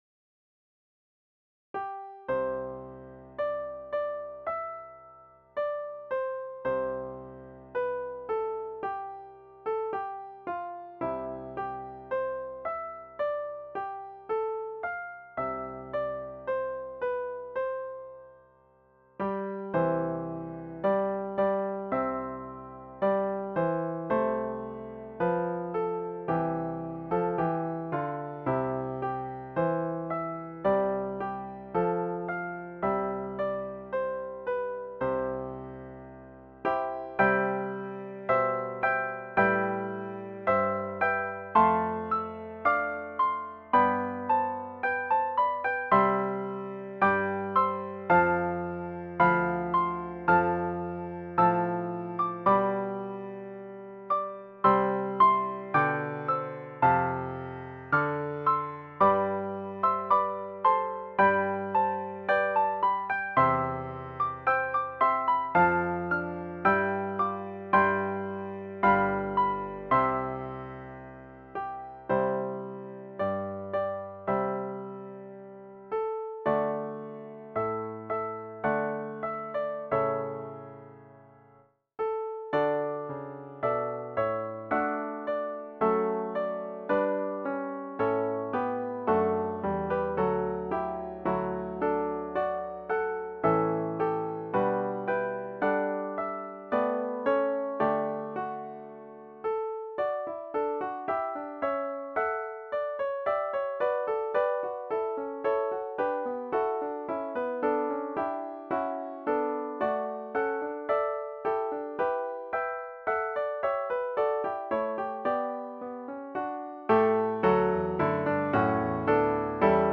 This is a piano duet arrangement of "The Spirit of God".
This arrangement starts with the melody simply played by the higher part. The lower part plays an octave chord. Harmony parts are added with each musical phrase and the first verse ends joyfully. In the second verse the higher part has a beautiful solo with a Baroque feel.
Voicing/Instrumentation: Piano Duet/Piano Ensemble We also have other 36 arrangements of " The Spirit of God ".